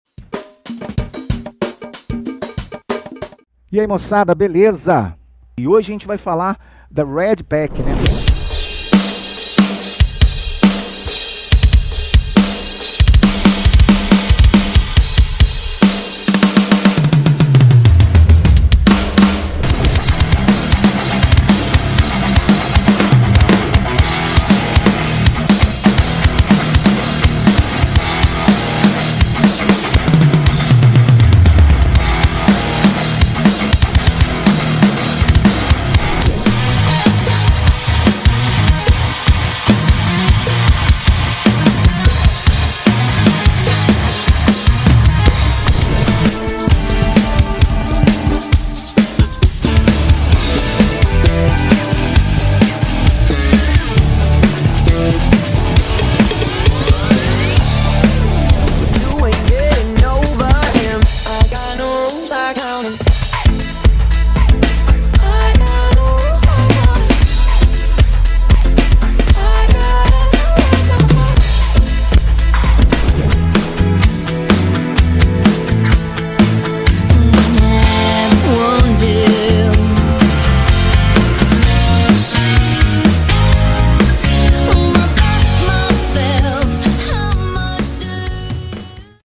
São sons minuciosamente gravados, bem processados, limpos, pesados.
They are meticulously recorded sounds, processed, clean, heavy.
It’s a soooo big drum sound!